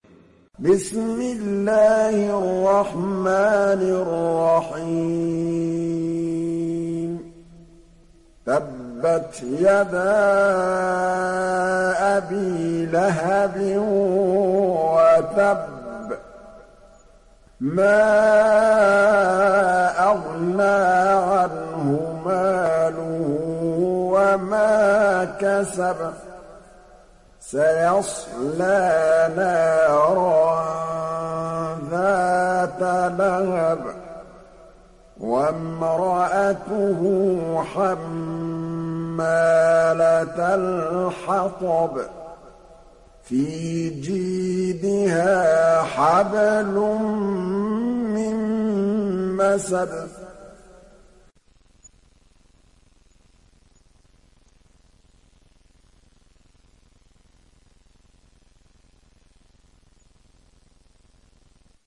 تحميل سورة المسد mp3 بصوت محمد محمود الطبلاوي برواية حفص عن عاصم, تحميل استماع القرآن الكريم على الجوال mp3 كاملا بروابط مباشرة وسريعة